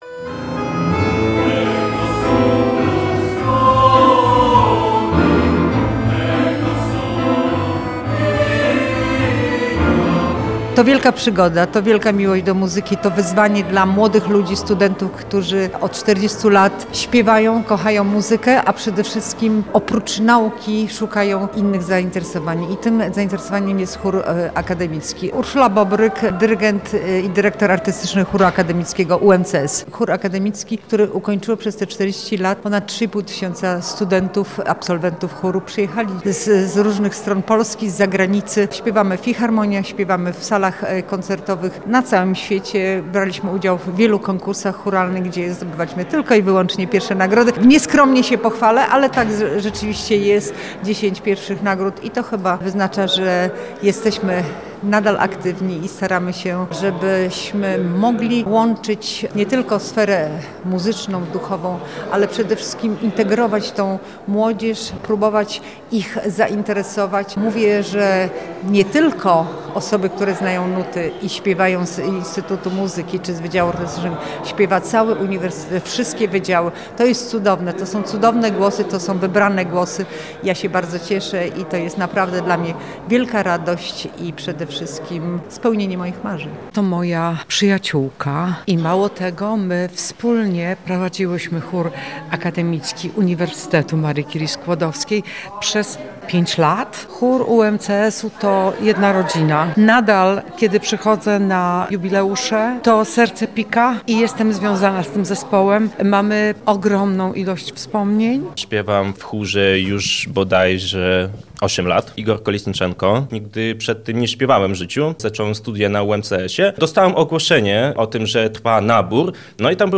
Podczas jubileuszowego koncertu Chór Akademicki UMCS również zaskoczył widownię swoim wykonaniem.
To był wyjątkowy wieczór, łączący sacrum i profanum – medytacyjny ton modlitwy z ogniem latynoamerykańskiego tango.